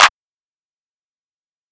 RacksRachet(clap).wav